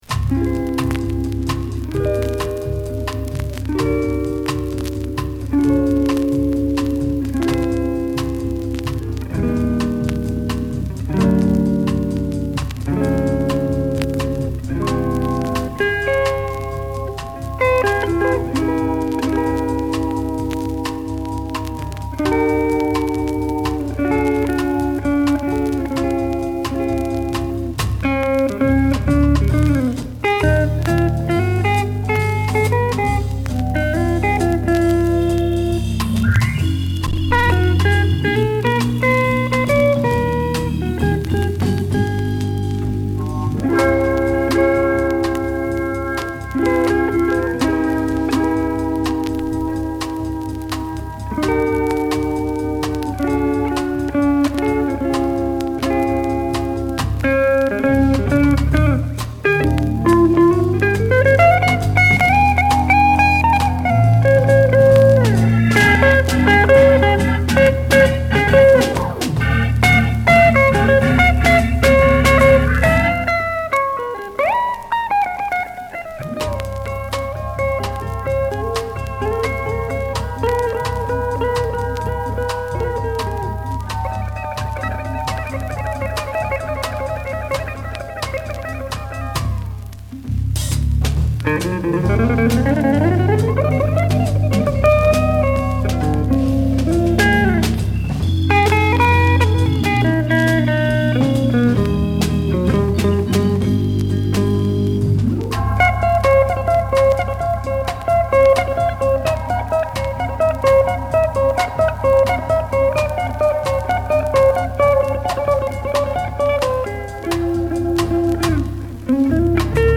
45rpm Record